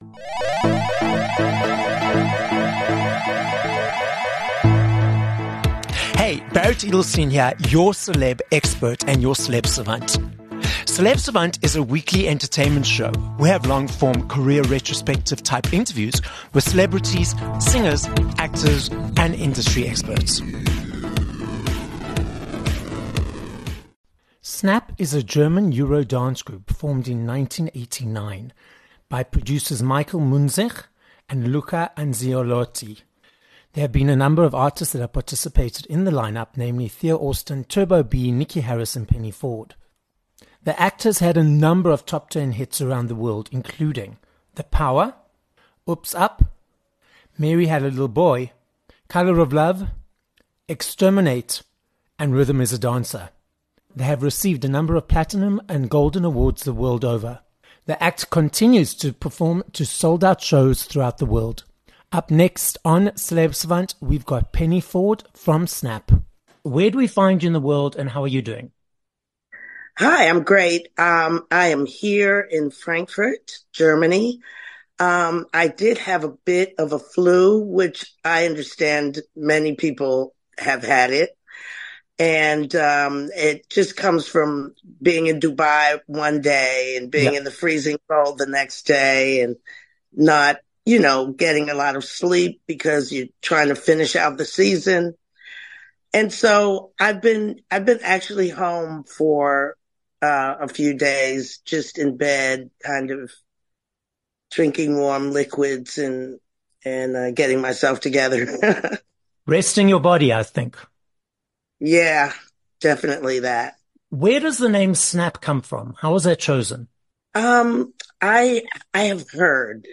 11 Jan Interview with Snap!